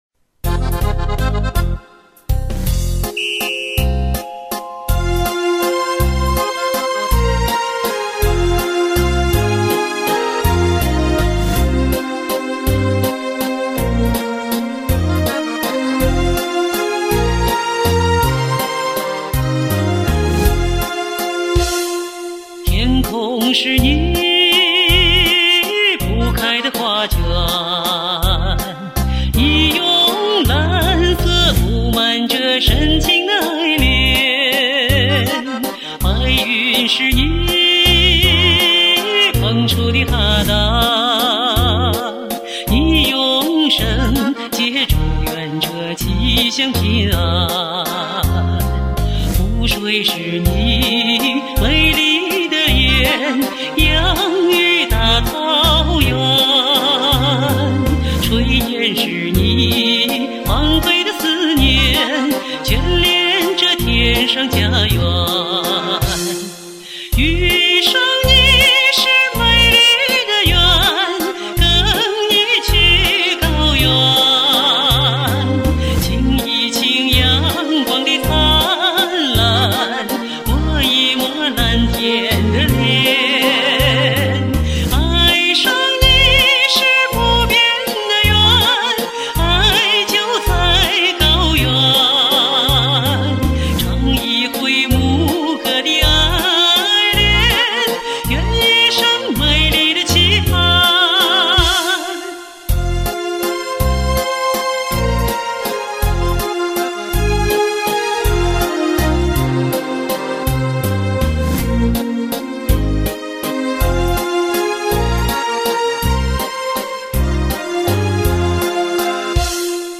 现在作为一名唱歌爱好者活跃在网络，擅长演唱草原歌曲